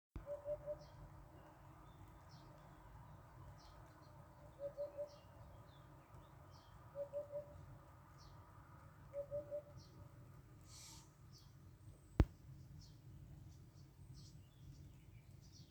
удод, Upupa epops
СтатусПоёт
ПримечанияDzirdēts pie mājas. 9.junijā redzēts 500m tālāk uz priežu meža stigas vācam barību knābī.